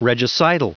Prononciation du mot regicidal en anglais (fichier audio)
Prononciation du mot : regicidal